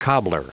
Prononciation du mot cobbler en anglais (fichier audio)
Prononciation du mot : cobbler